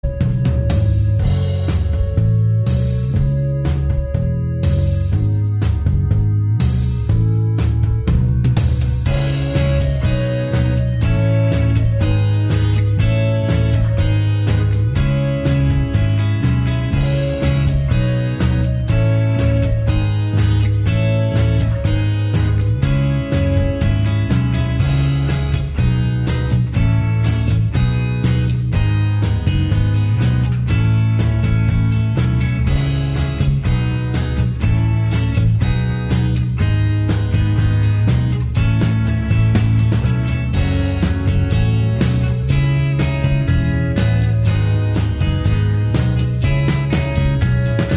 Minus Acoustic Guitars Indie / Alternative 4:29 Buy £1.50